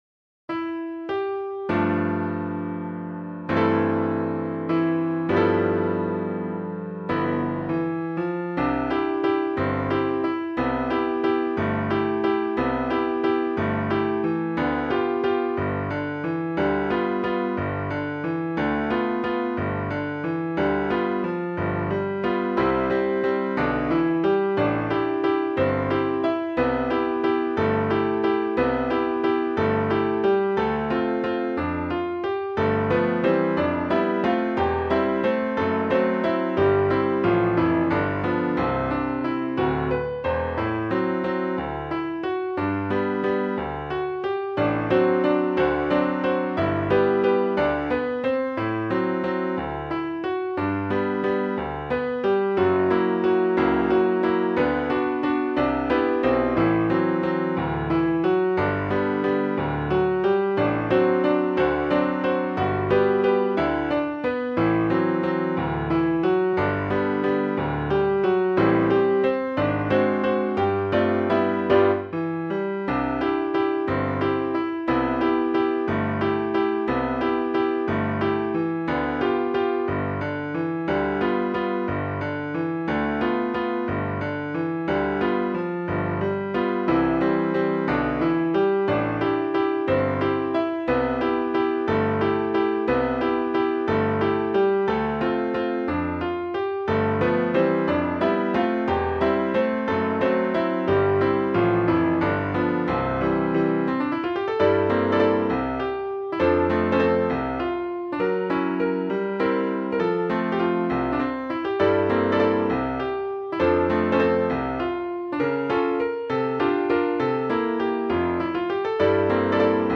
心地よい束の間の昼寝時間、、、、そんな表現がぴったりのサティのアンニュイ感。
物憂げに漂う安定の３拍子とはいえ、なんといってもハ長調。
リズミカルながら静かでゆっくり目の３拍子、そして左右を行き来する旋律。